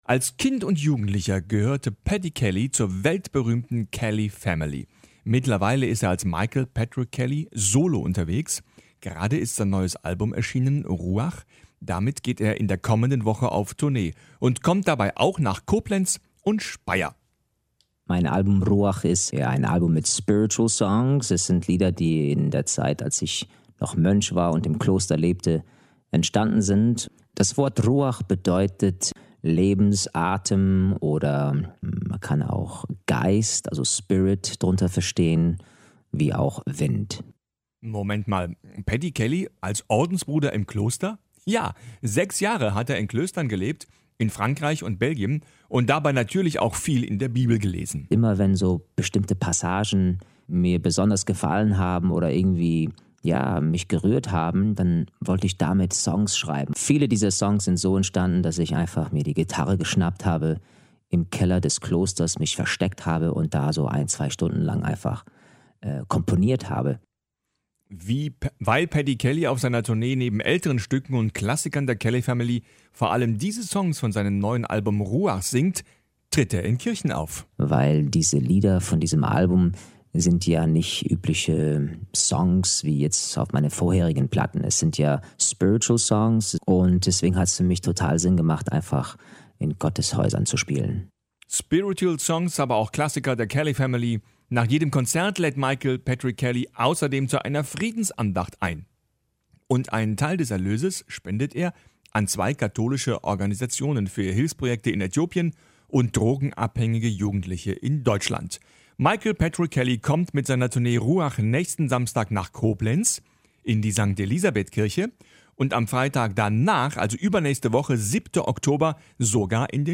Ein Beitrag von RPR1 zum Auftritt von Patrick Kelly im Dom